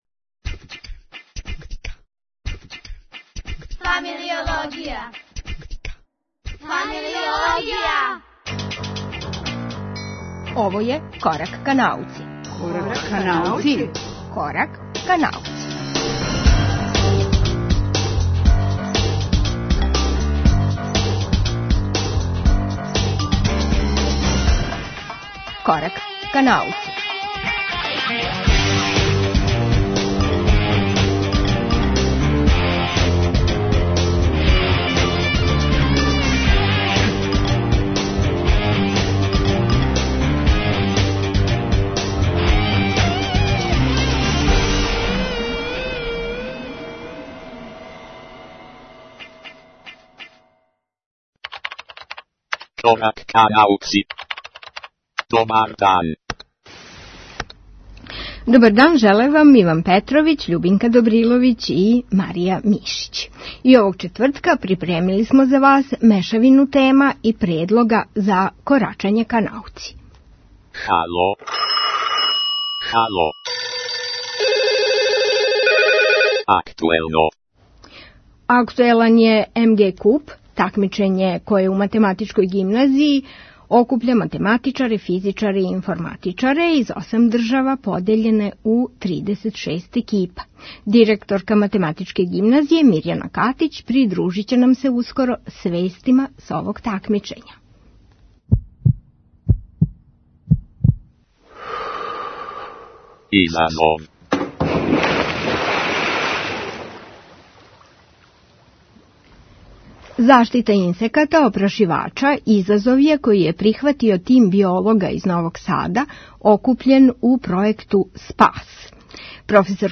Емисију чини колаж активности (осврт на Куп Математичке гимназије), изазова (разговор о пројекту Спас који треба да допринесе очувању опрашивача) и редовних рубрика Један је Галоа, Отворена лабораторија, Слободна зона и Шест немогућих ствари пре ручка.